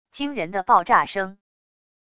惊人的爆炸声wav音频